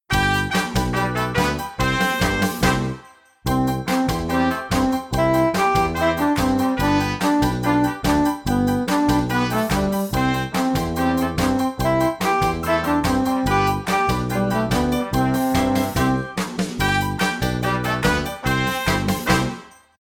Backing track:
Birralii-Wii-dha_Backing-track-(slower).mp3